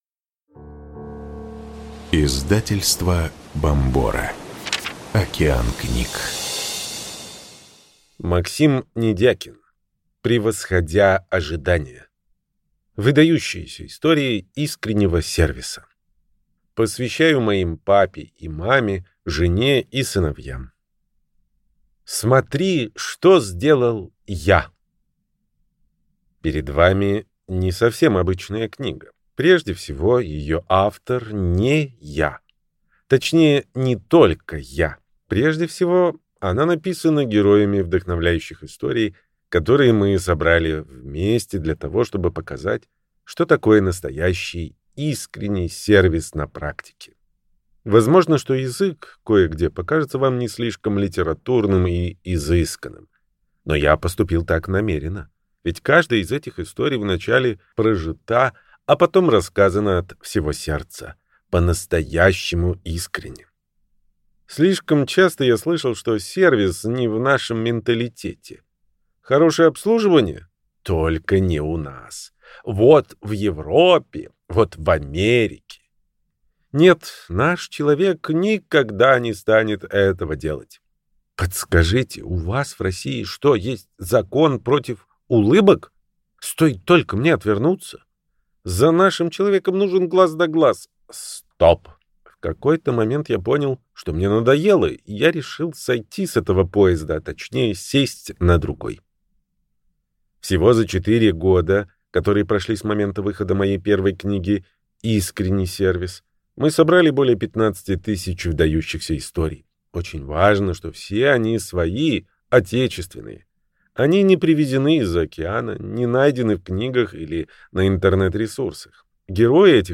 Аудиокнига Превосходя ожидания. Выдающиеся истории искреннего сервиса | Библиотека аудиокниг